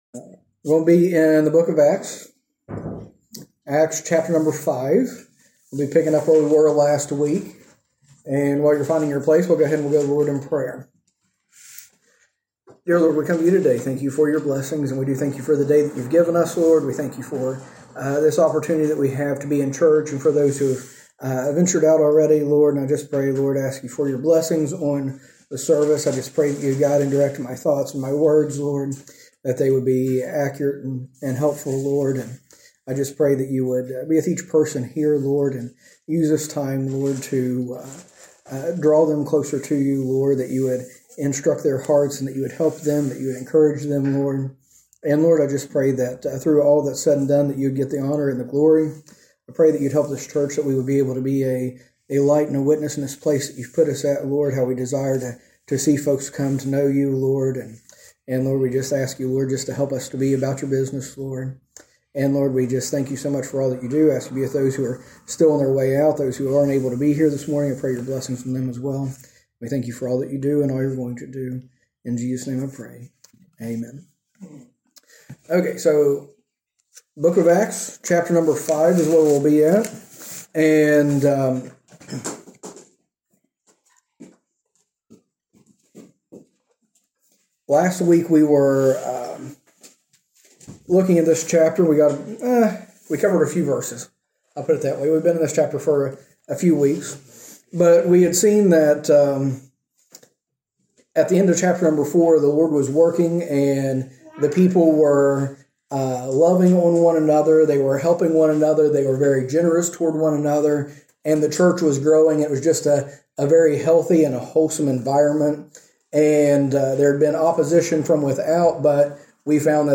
A message from the series "Acts Bible Study."